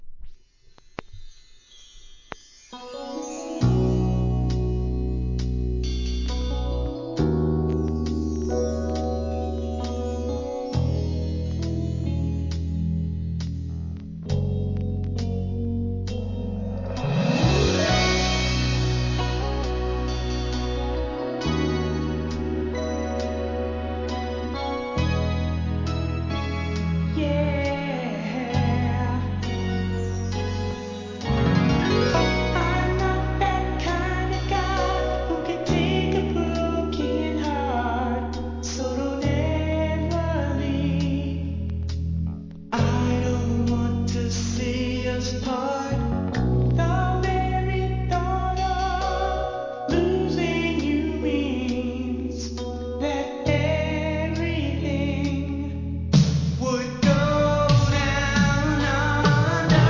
(イントロに数発ノイズ)
SOUL/FUNK/etc...